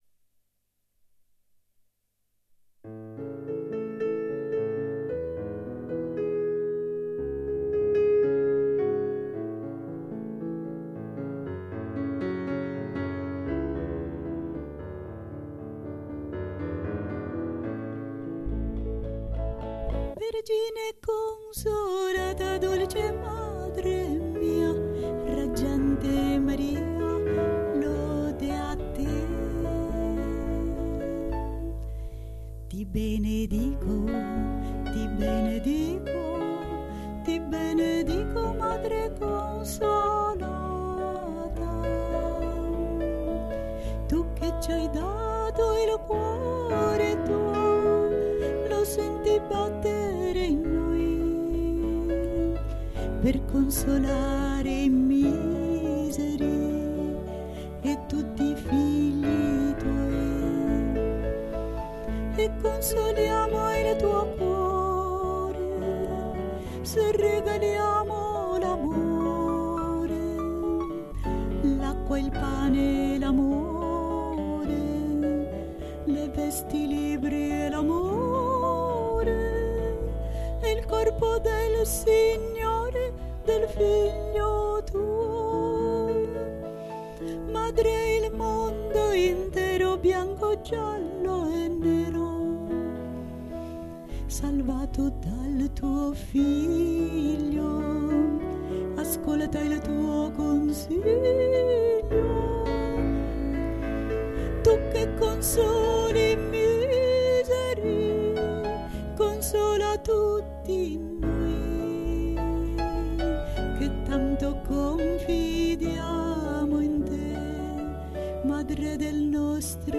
Vergine Consolata canto mp3